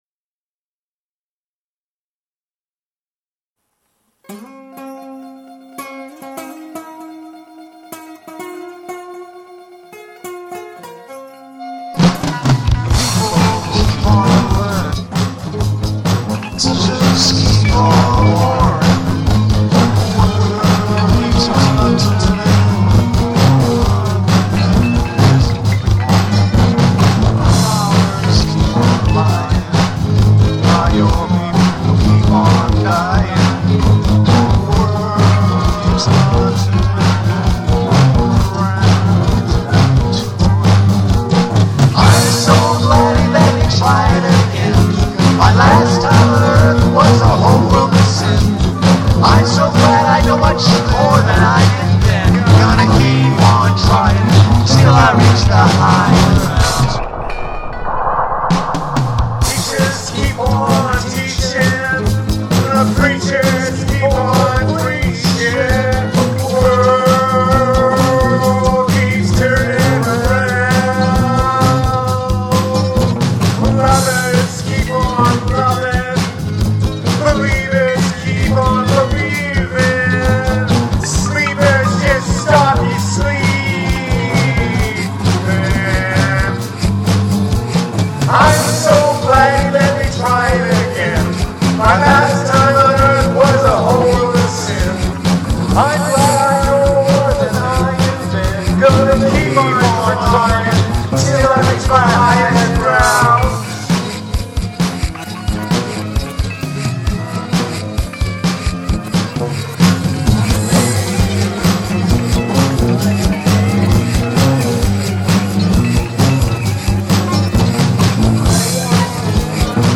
Prog rock